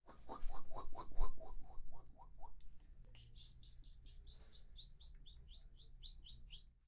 音频1 " 丛林动物之声
Tag: 自然 动物 野生动物 声音